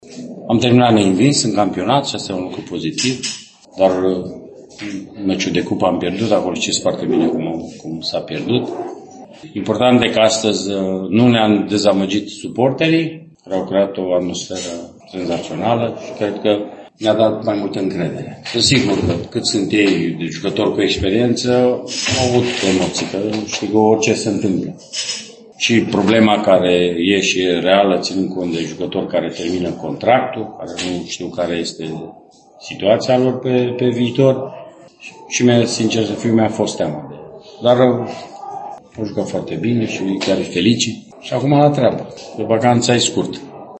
Tehnicianul Bătrânei Doamne a mai remarcat cifra ”zero” din dreptul înfrângerilor (în campionat) de când a preluat echipa: